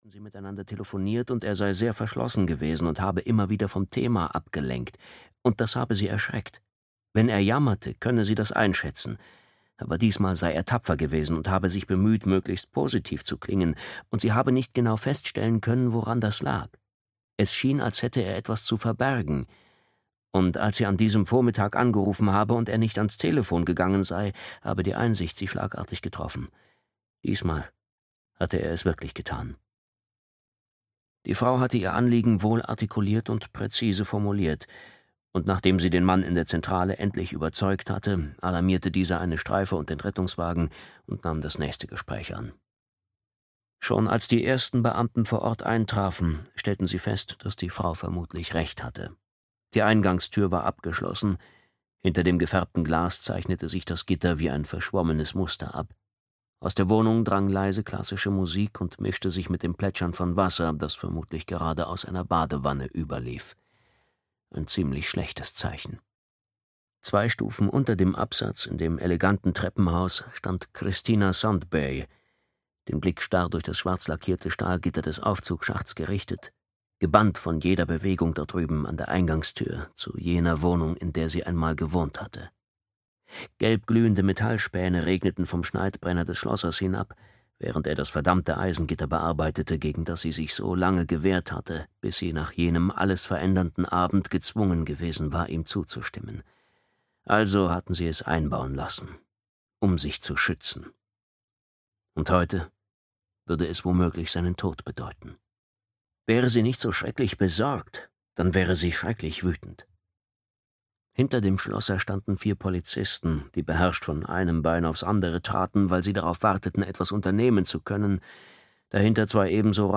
Der Code - Fredrik T. Olsson - Hörbuch